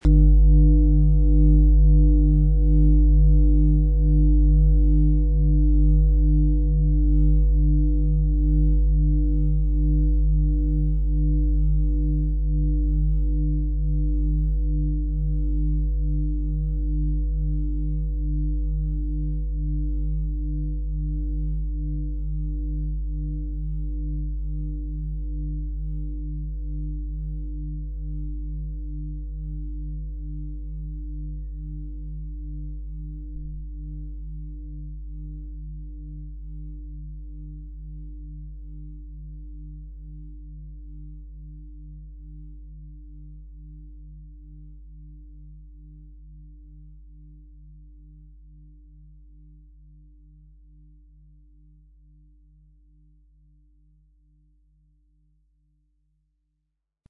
XXXL-Fußreflexzonen-Klangschale – Weite Klänge für tiefe Erdung
Ein sanfter Schlag genügt, und die Schale entfaltet tiefe Töne, die dich erden und entspannen.
So trägt sie eine einzigartige Klangsignatur in sich – lebendig, authentisch und voller Charakter.
MaterialBronze